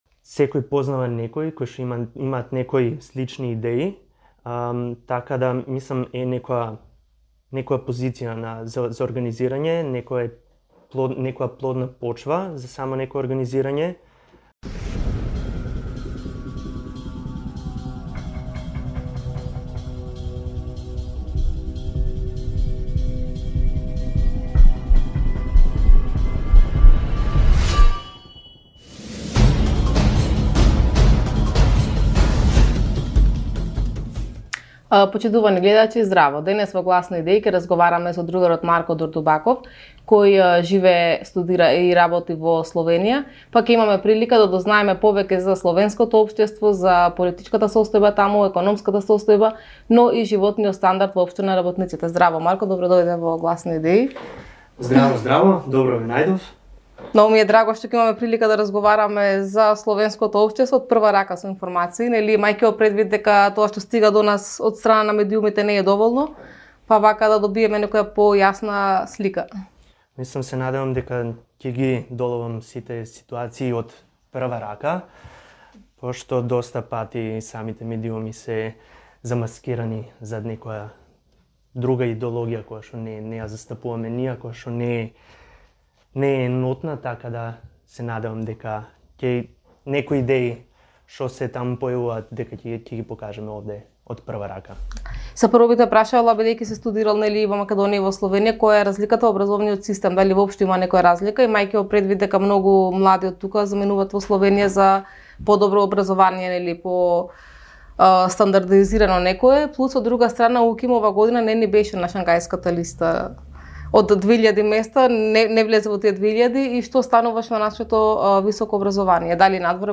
ИНТЕРВЈУ: Како да се организираат младите?